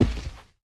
25w18a / assets / minecraft / sounds / mob / panda / step5.ogg
step5.ogg